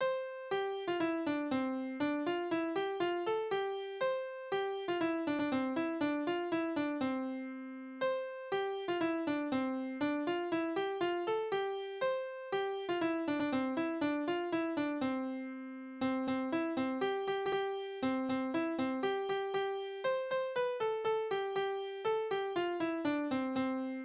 Tanzverse: Schüttelbüx
Tonart: C-Dur
Taktart: 2/4
Tonumfang: Oktave
Besetzung: vokal